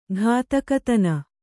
♪ ghātakatana